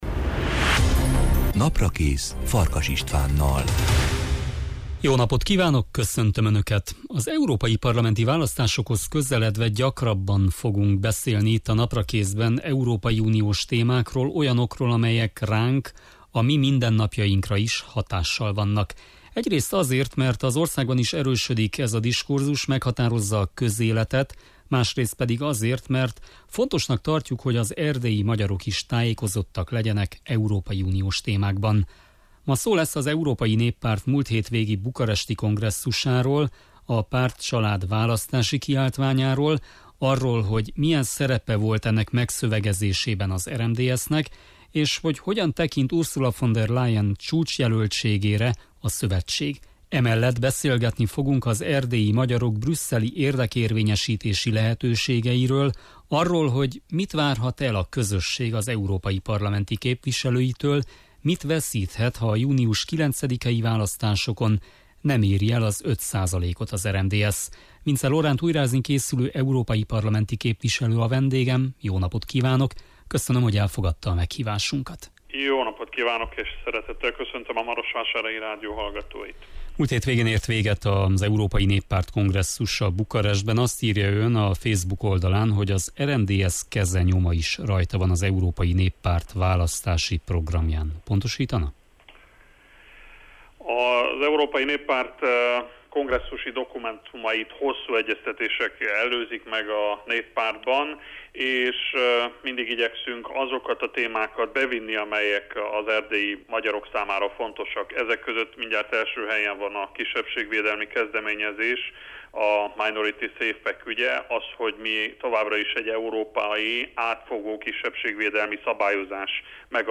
Vincze Loránt, újrázni készülő EP-képviselő a Naprakész vendége.